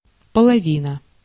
Ääntäminen
IPA: [də.mi]